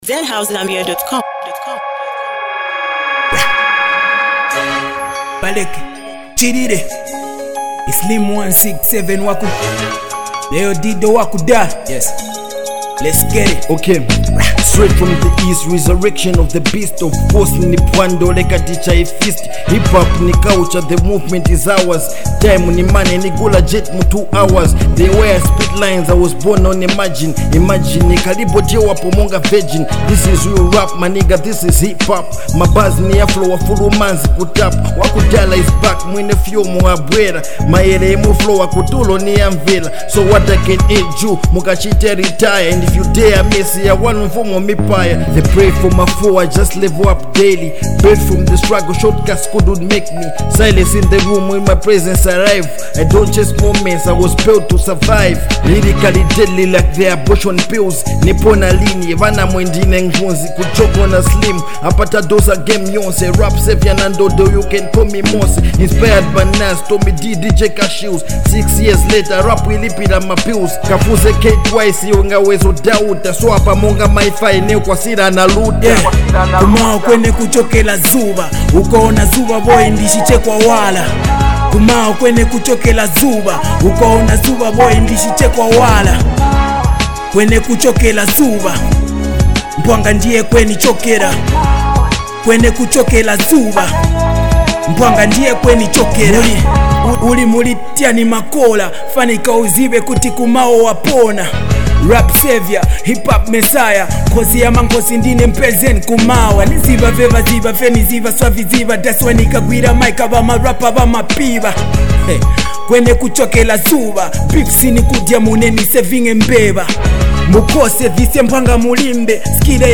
delivering bar after bar with unmatched intensity.